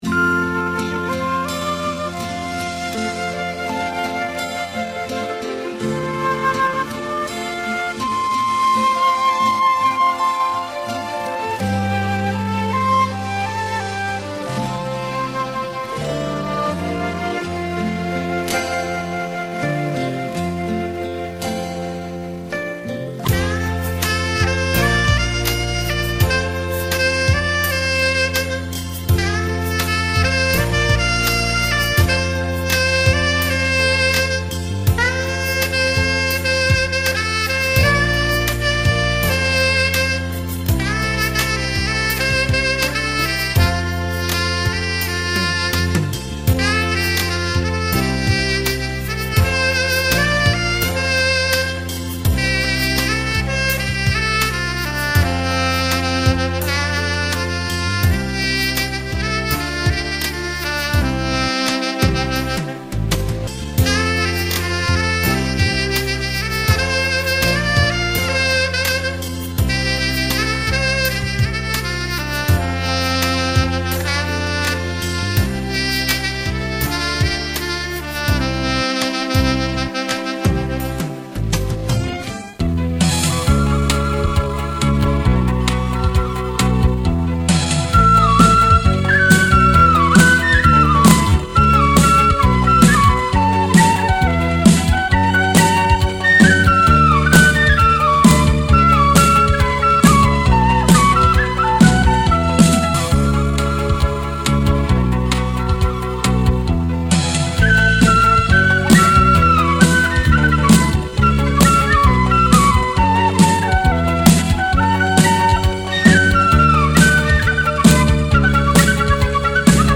[26/4/2009]乐器联奏